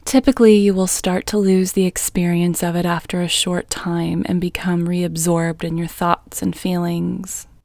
QUIETNESS Female English 20
Quietness-Female-20-1.mp3